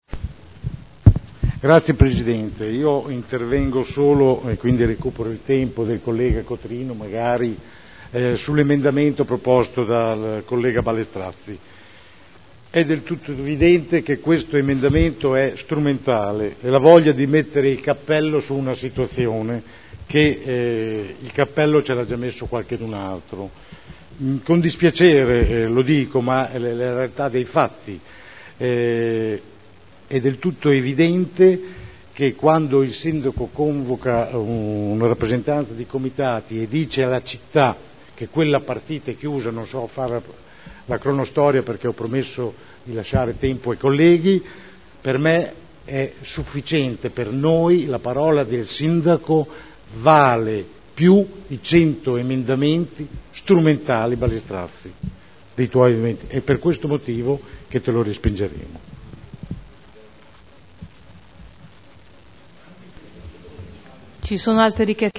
Francesco Rocco — Sito Audio Consiglio Comunale
Seduta del 4 luglio 2011. Bilancio di previsione 2011 – Bilancio pluriennale 2011-2013 – Programma Triennale dei Lavori Pubblici 2011-2013 – Variazione di Bilancio n. 1 Dibattito